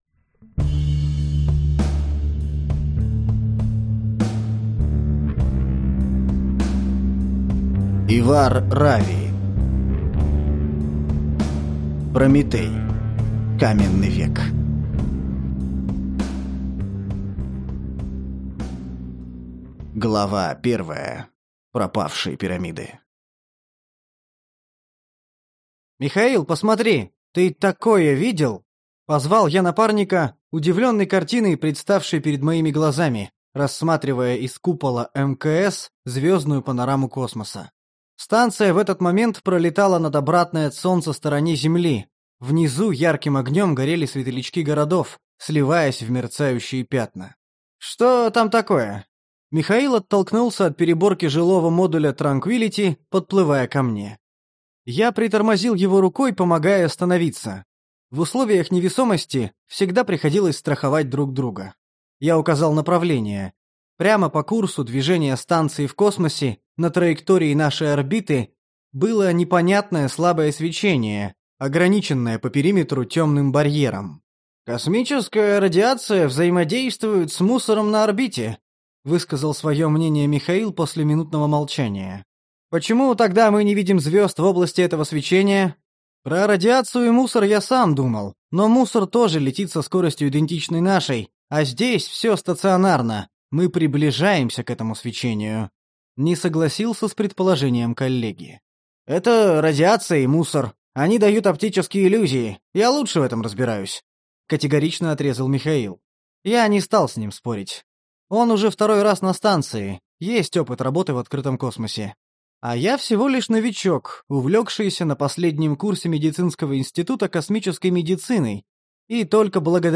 Аудиокнига Прометей: Каменный век | Библиотека аудиокниг